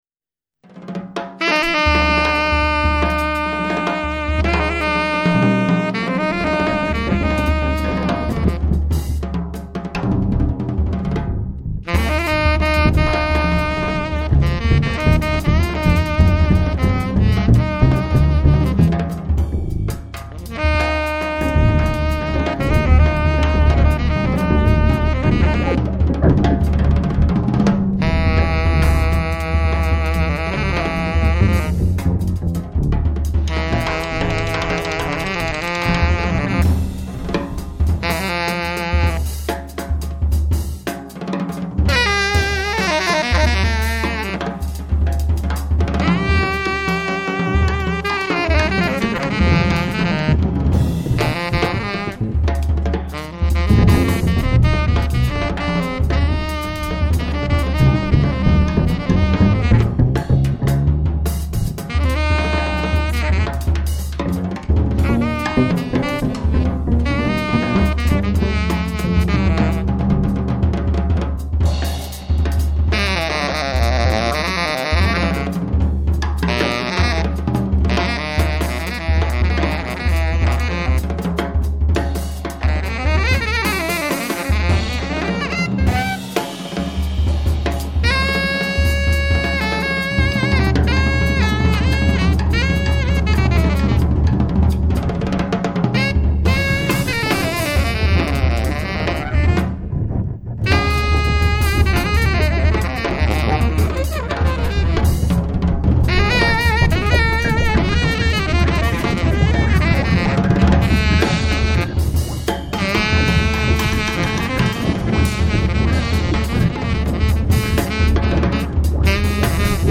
Recorded at Flokets Hus, Oslo,